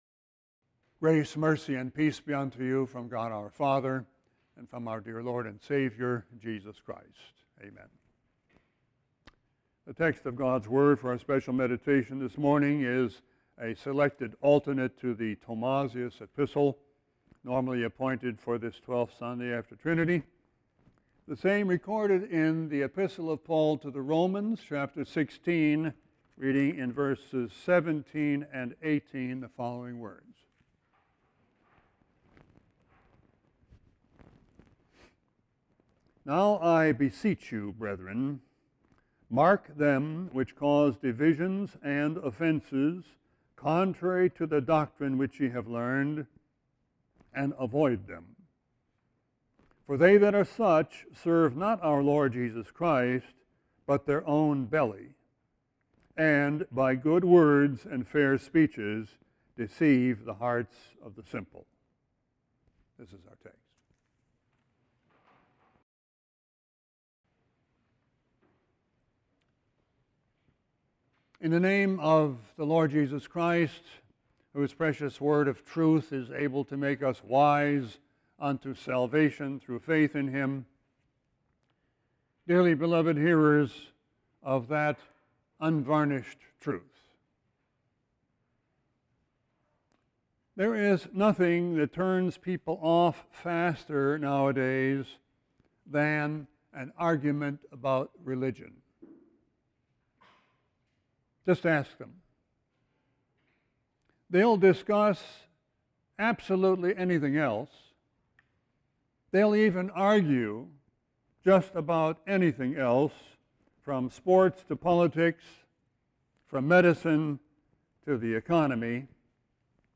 Sermon 8-19-18.mp3